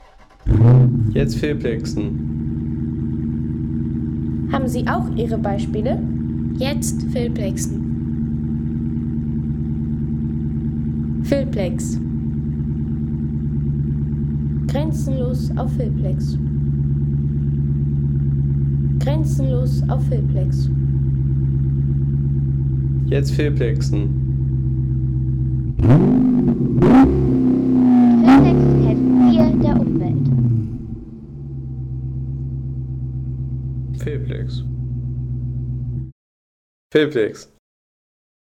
Dodge Charger SRT 392 – V8 Sounderlebnis
Erleben Sie den tiefen, kraftvollen Sound eines Dodge Charger SRT 392 – vom ersten Motorstart bis zum donnernden Drehzahlspiel des 6,4‑Liter‑V8‑Hemi.